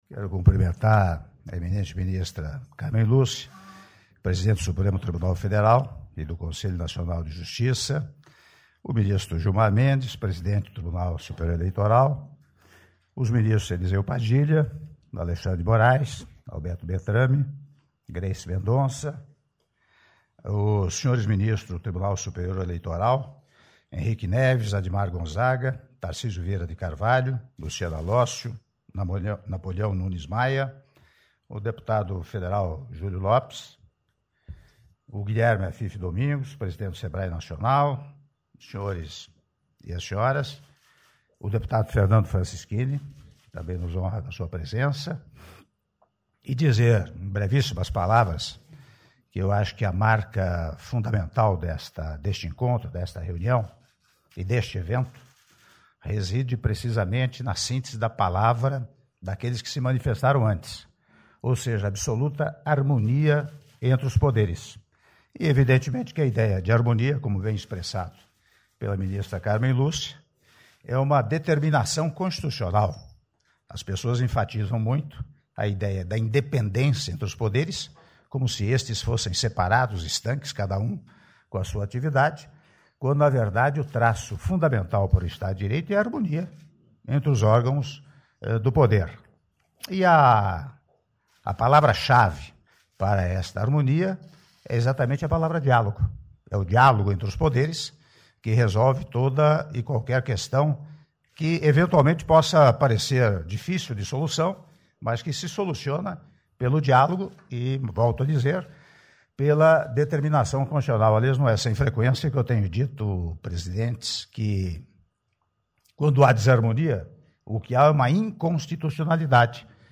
Áudio do discurso do presidente da República, Michel Temer, durante a cerimônia de assinatura de Acordo de Cooperação Técnica com o Tribunal Superior Eleitoral - Brasília/DF (08min13s)